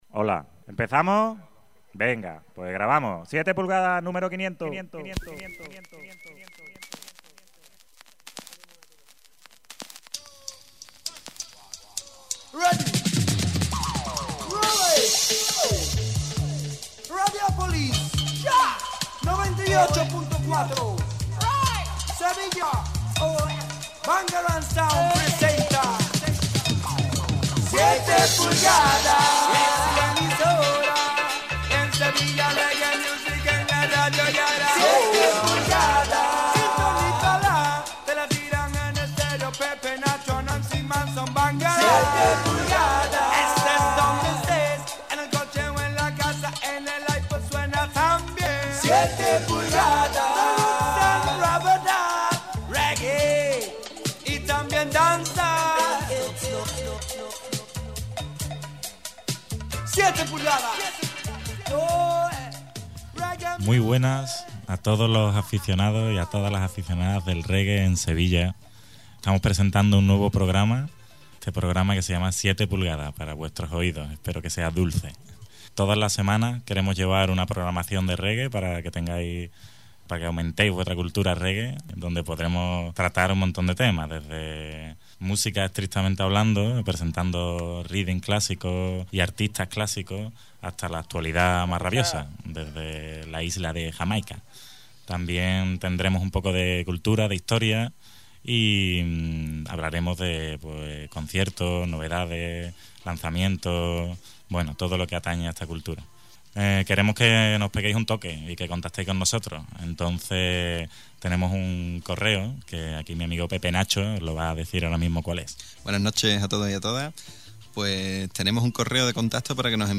Presentado y dirigido por la Bangarang Sound y grabado el 30 de agosto en El Mirador de Estrellas, Posada La Niña Margarita (Aldea de los Villares, Priego de Córdoba), gracias a nuestros amigos de Suburb Sound .